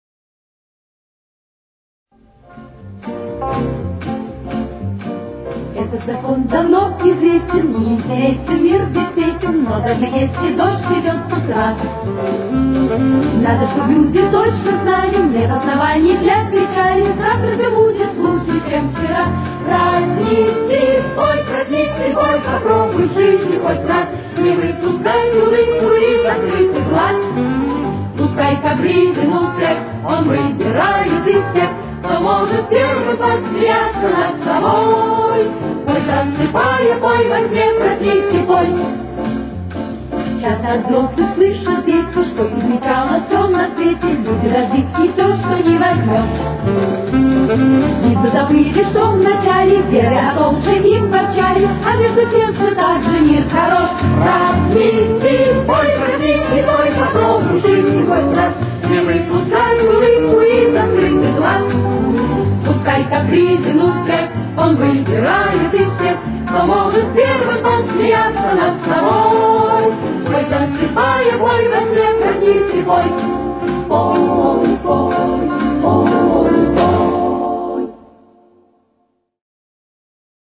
Тональность: Фа мажор. Темп: 129.